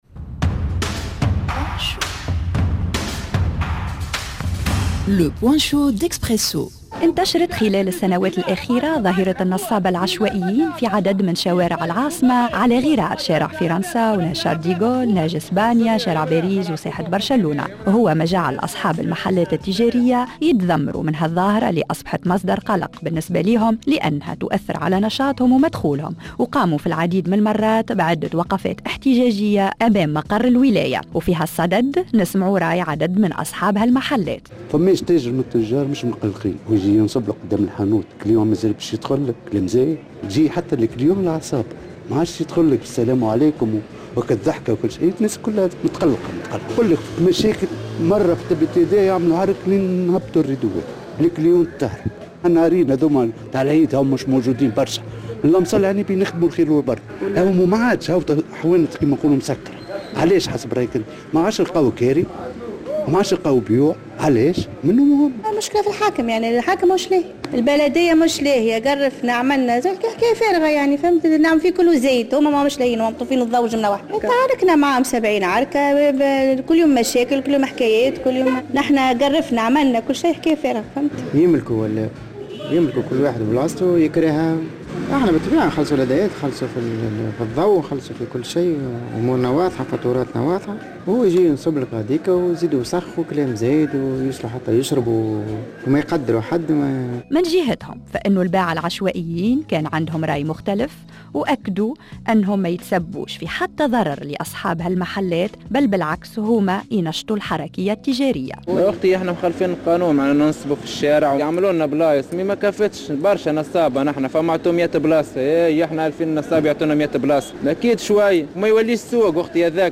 الإنتصاب الفوضوي في شوارع العاصمة، ظاهرة تستفحل يوم بعد يوم ريبورتاج